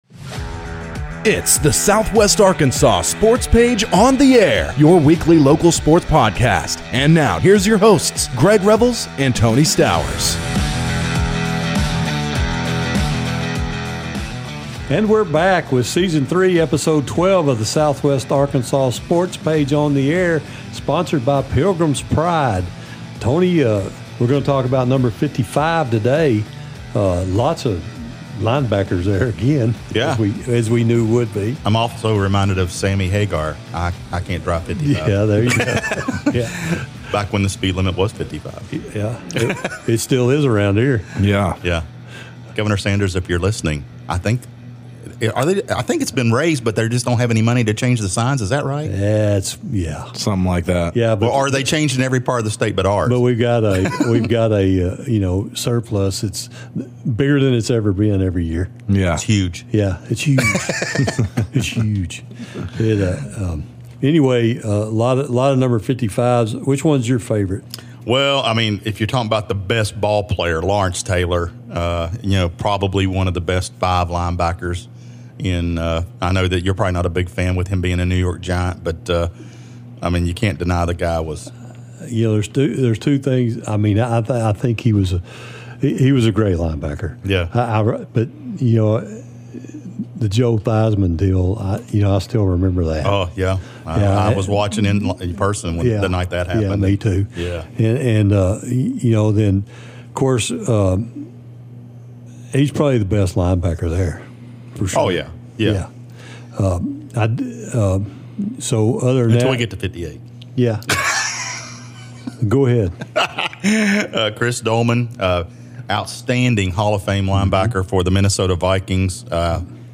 calls in to the show